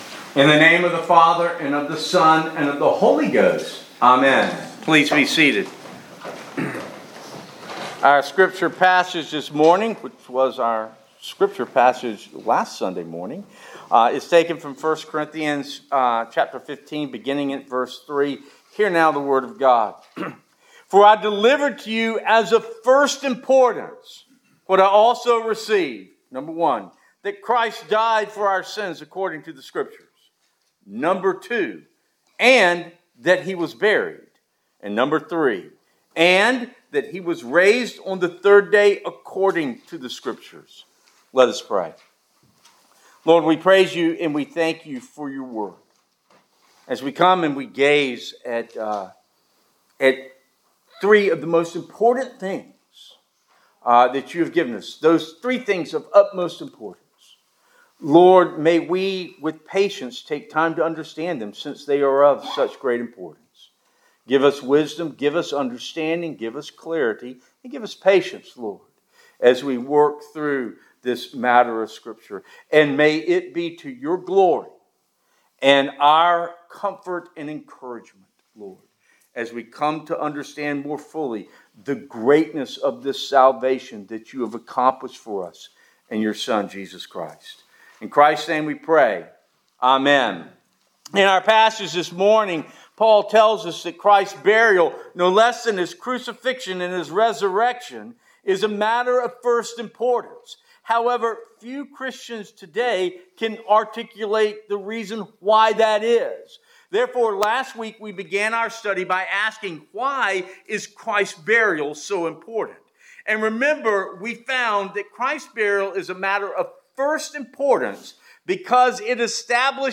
Therefore, by the end of last week’s sermon we had a delay that is defined by promise (not judgment).